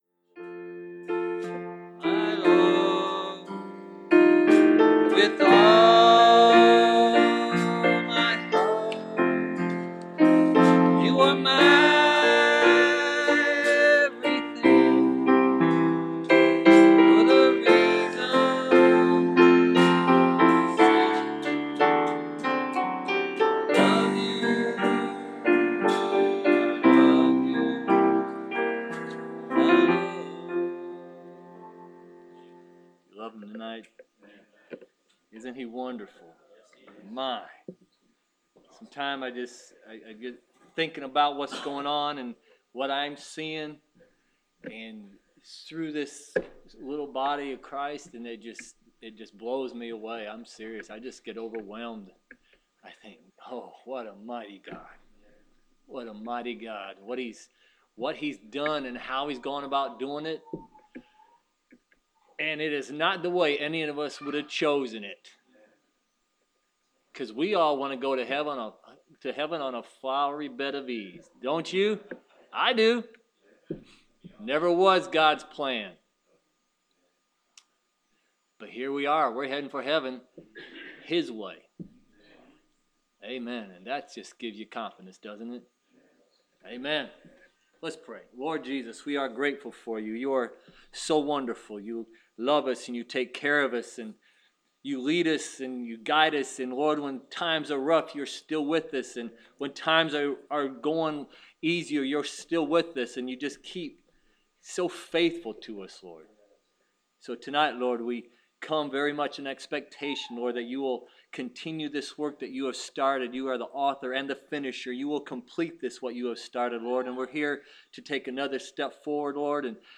Preached March 3, 2016